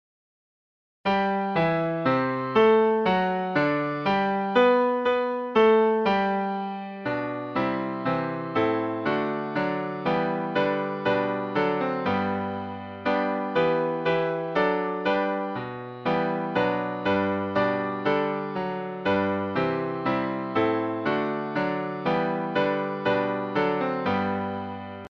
Hymns of praise
Welsh melody